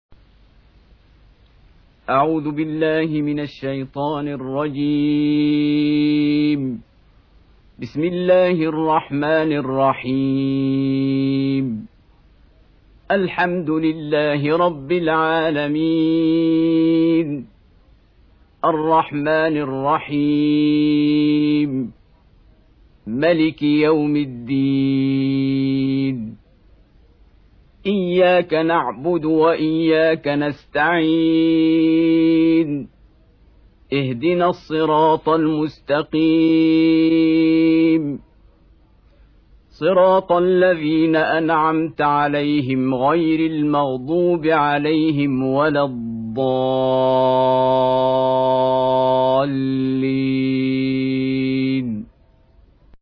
Surah Repeating تكرار السورة Download Surah حمّل السورة Reciting Murattalah Audio for 1. Surah Al-F�tihah سورة الفاتحة N.B *Surah Includes Al-Basmalah Reciters Sequents تتابع التلاوات Reciters Repeats تكرار التلاوات